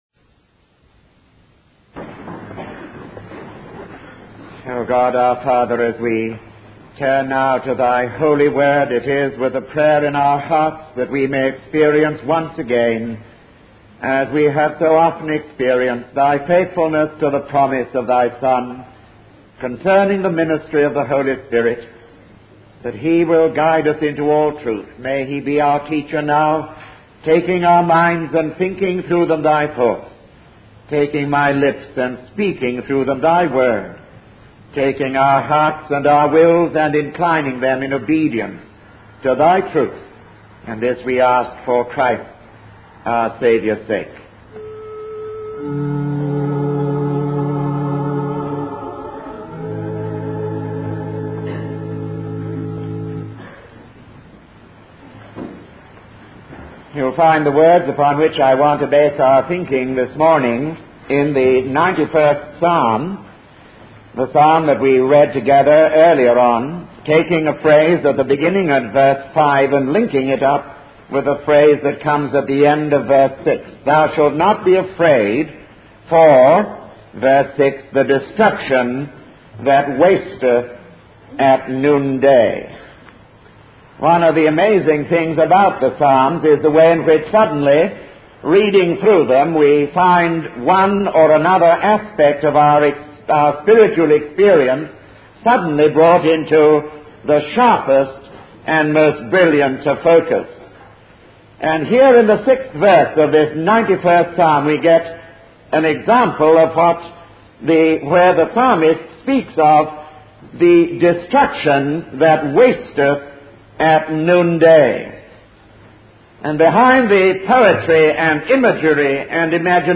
In this sermon, the speaker emphasizes the importance of dwelling in a secret place with God, which is the most high.